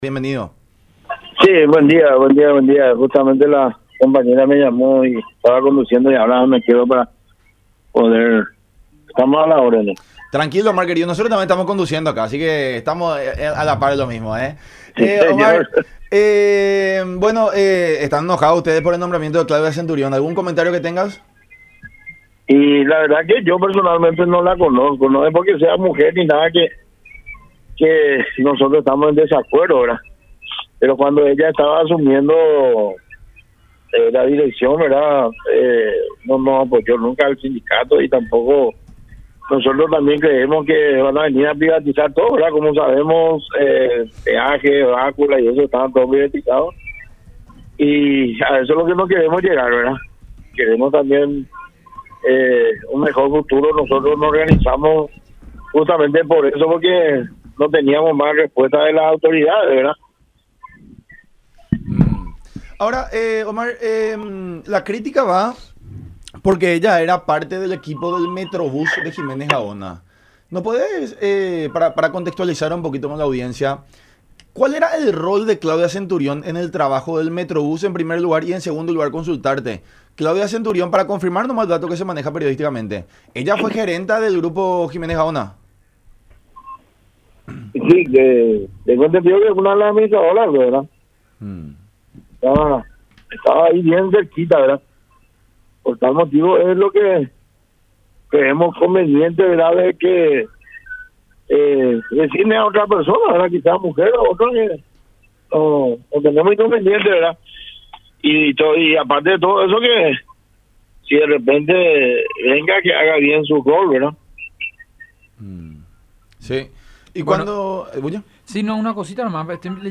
en charla con La Unión Hace La Fuerza por Unión TV y radio La Unión.